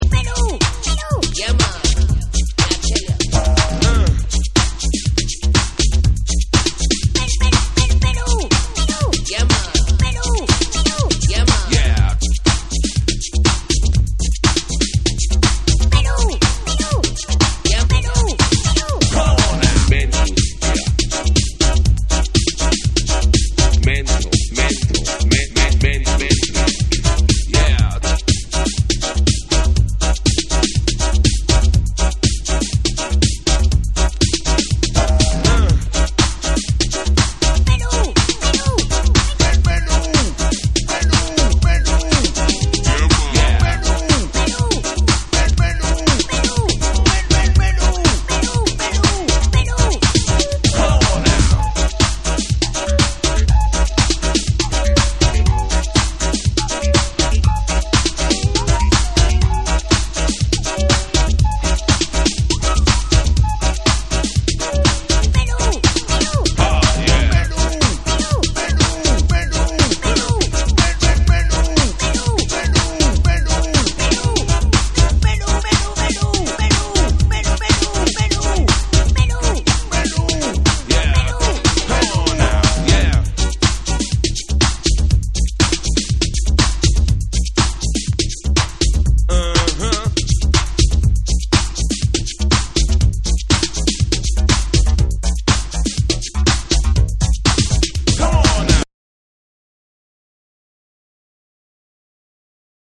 哀愁漂うホーンが印象的なキラー・スカを見事アシッドハウスに落とし込みラップを乗せた1
TECHNO & HOUSE